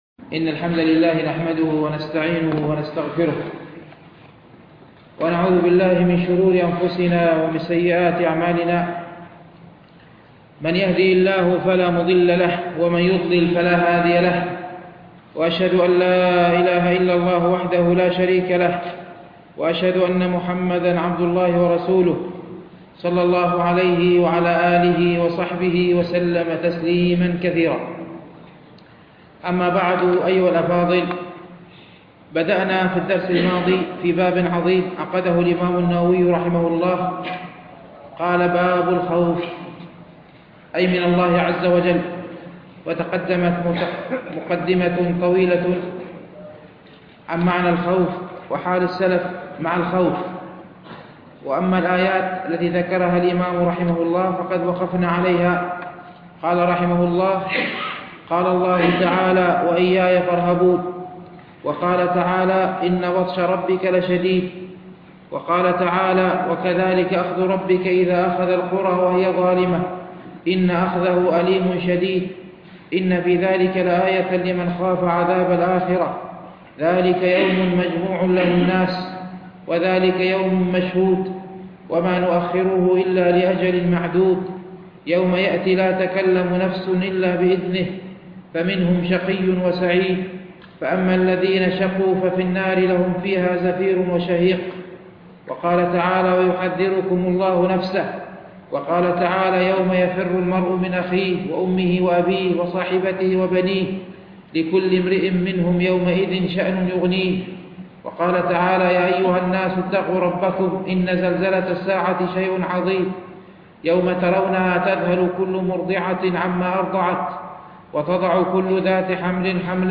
شرح رياض الصالحين - الدرس السابع عشر بعد المئة